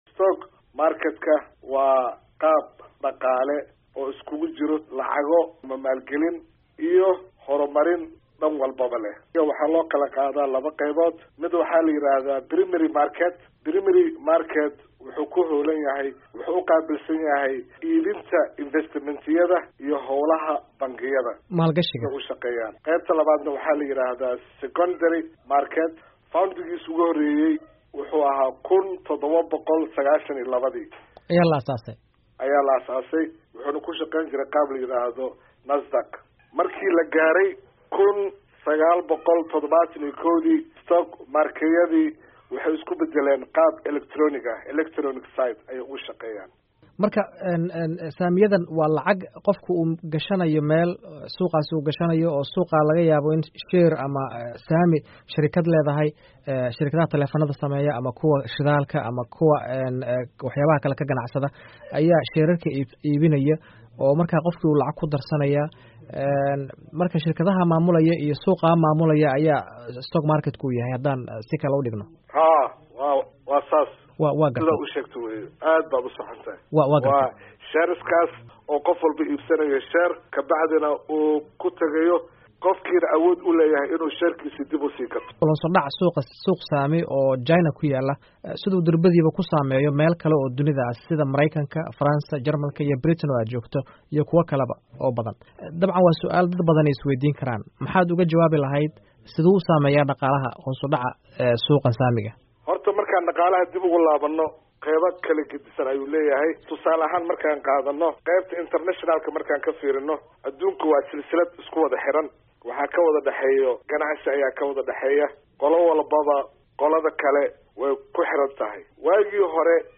Wareysi: Suuqyada Saamiyada